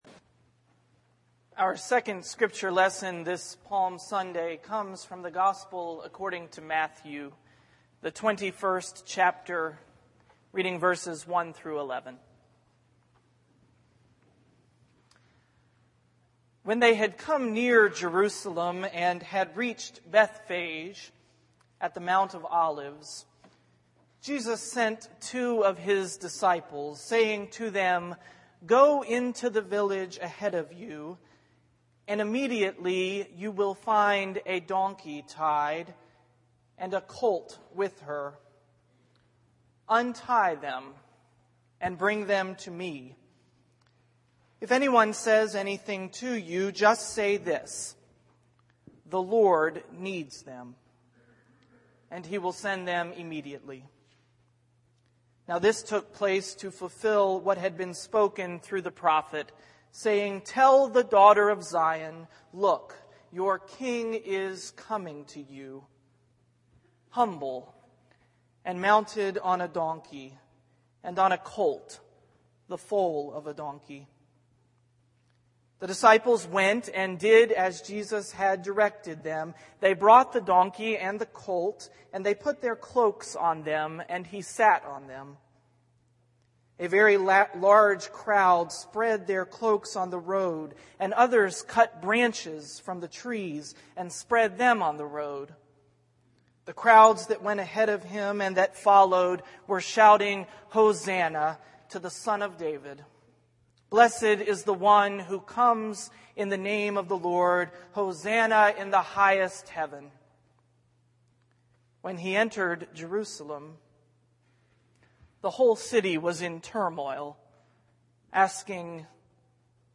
worship_mar16_sermon.mp3